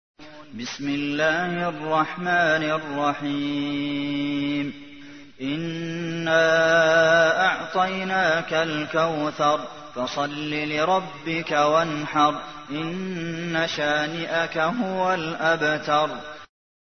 تحميل : 108. سورة الكوثر / القارئ عبد المحسن قاسم / القرآن الكريم / موقع يا حسين